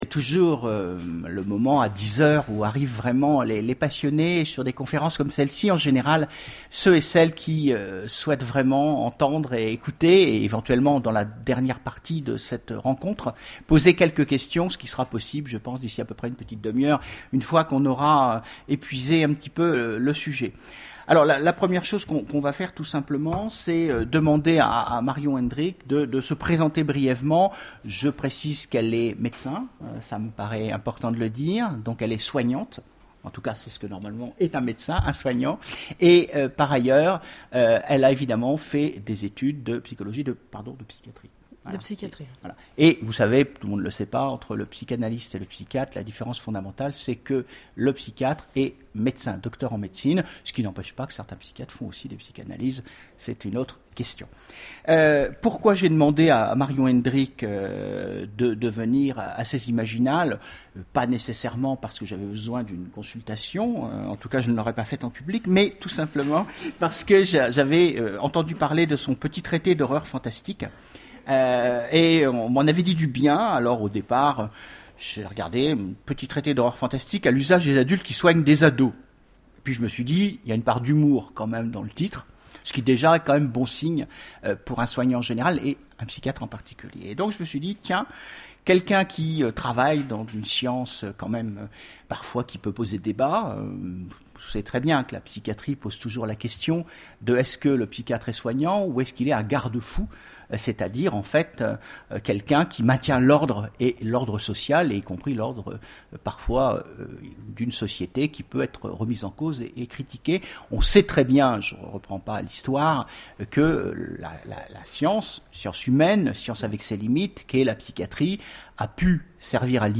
Imaginales 2013 : Conférence Petit traité d'horreur fantastique...